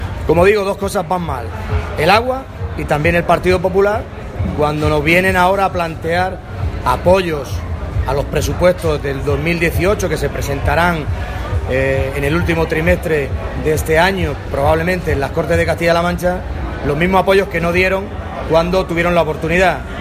El parlamentario regional ha realizado estas declaraciones en un encuentro con los medios de comunicación que ha tenido lugar en el stand del PSOE, en la Feria de Albacete.
Cortes de audio de la rueda de prensa